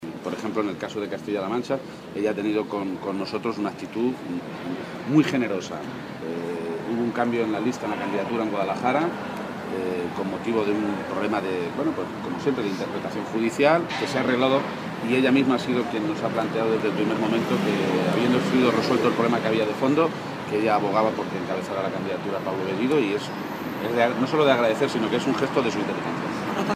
García-Page, que realizaba estas declaraciones a la entrada a la reunión del Comité Federal que el PSOE celebra hoy en Madrid, aseguró que los socialistas no se pueden conformar con un techo de 90 diputados, “sino que tenemos que salir a ganar para conseguir una mayoría clara que evite lo que hemos vivido durante los últimos cuatro meses”.
Cortes de audio de la rueda de prensa